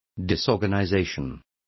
Complete with pronunciation of the translation of disorganization.